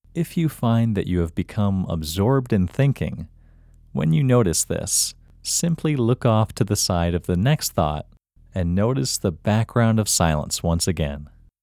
Locate IN English Male 23
Locate-IN-Male-23.mp3